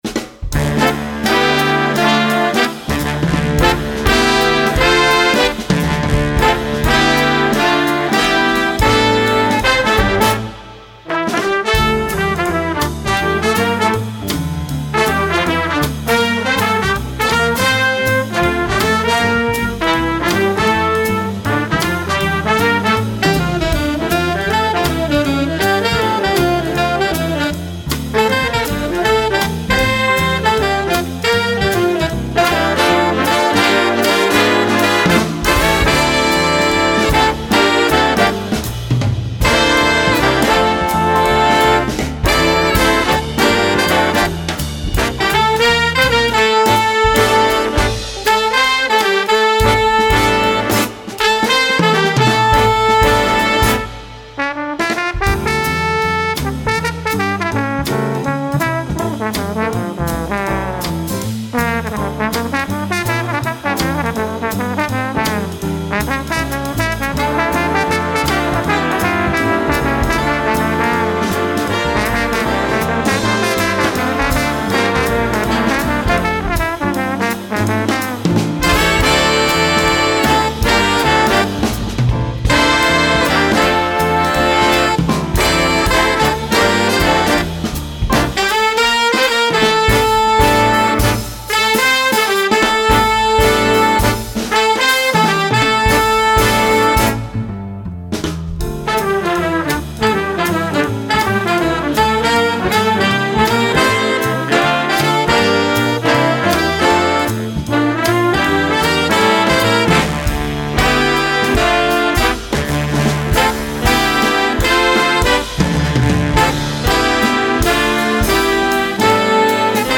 Instrumental Jazz Band Swing
This bright swing for beginning groups